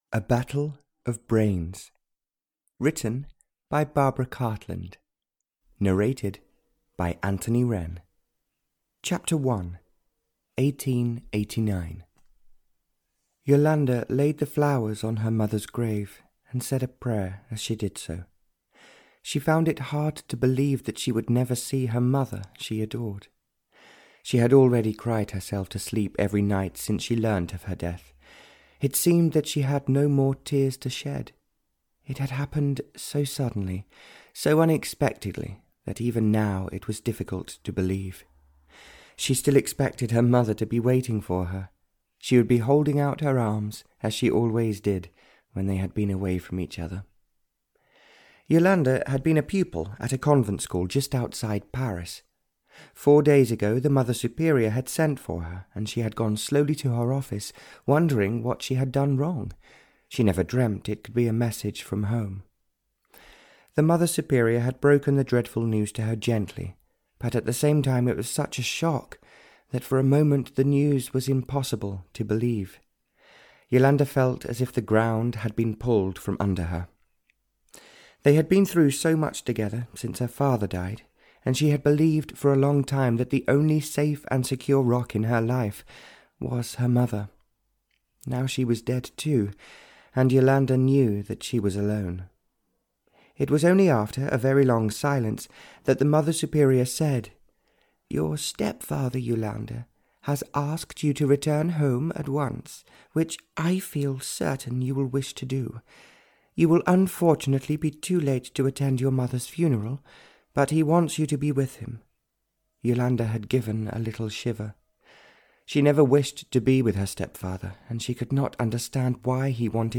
Audio knihaA Battle Of Brains (Barbara Cartland’s Pink Collection 60) (EN)
Ukázka z knihy